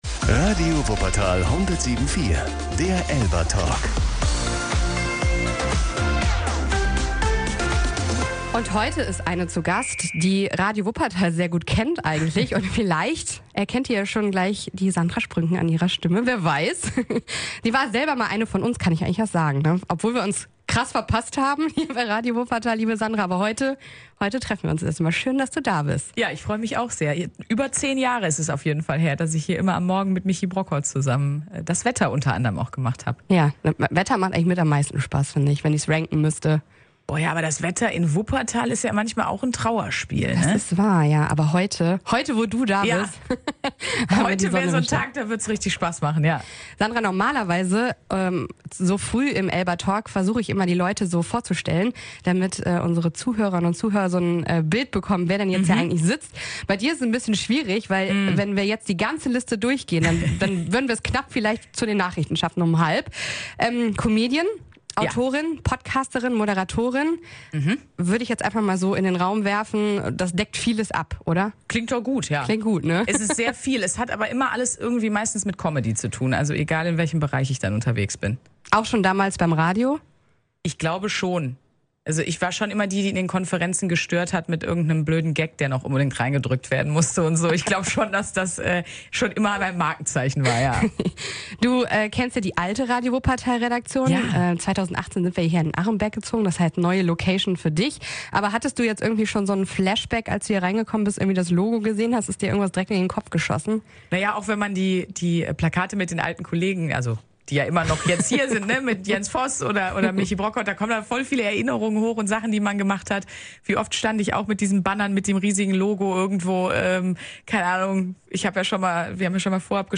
ELBA-Talk